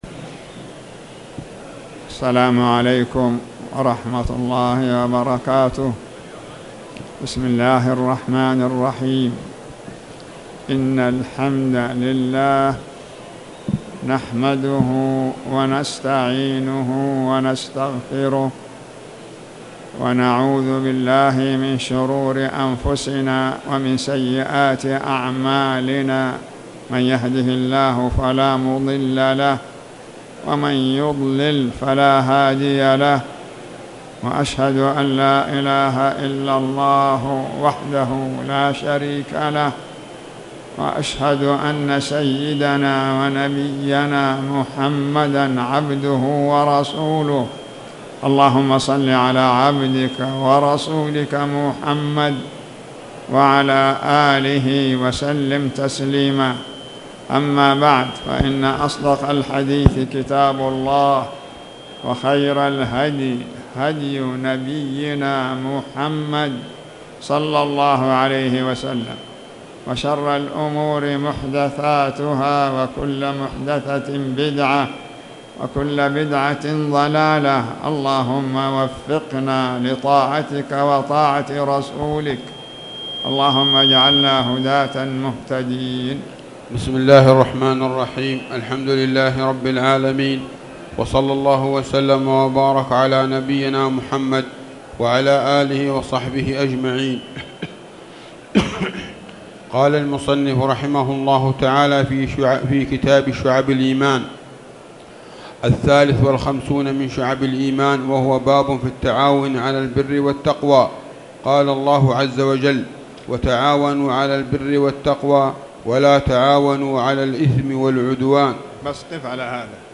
تاريخ النشر ٢٨ رجب ١٤٣٨ هـ المكان: المسجد الحرام الشيخ